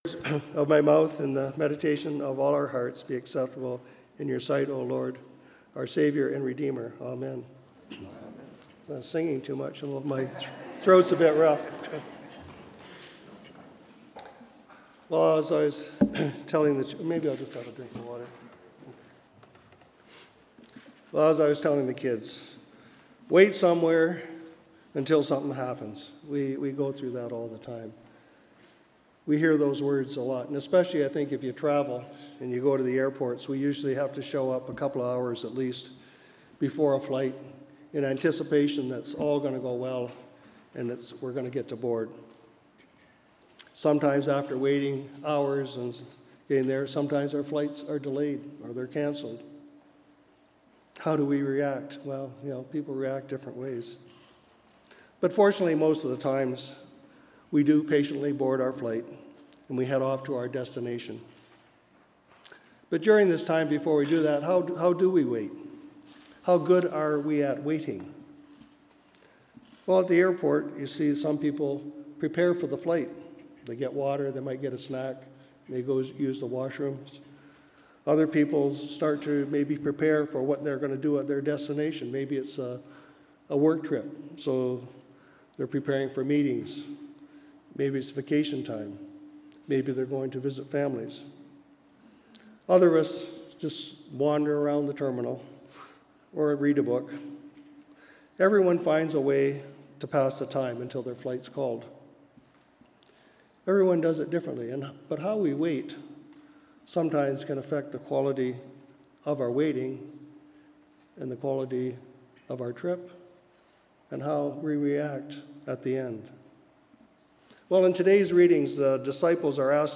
Sermons | St. Paul's Anglican Church